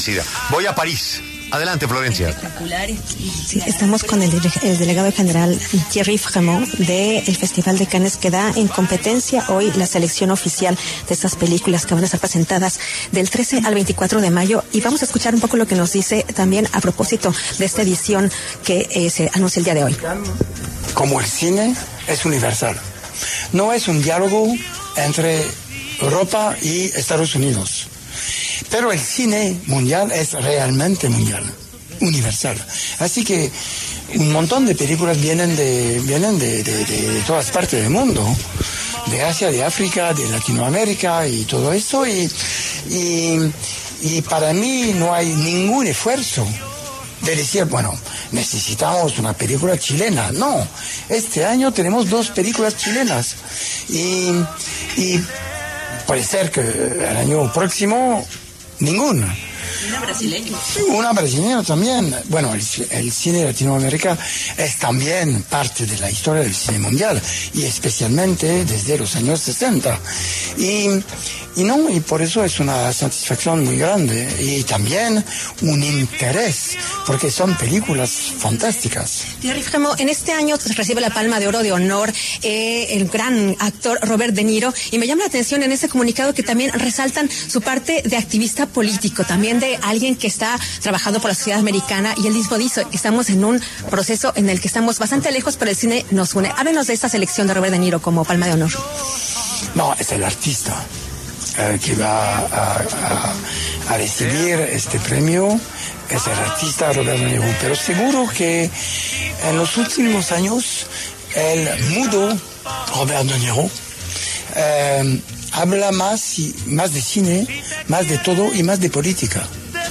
El delegado general del Festival de Cannes, Thierry Frémaux, habló en La W sobre la previa de este evento.